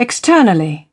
11. externally (v) /ɪkˈstɜːnəli/ : bên ngoài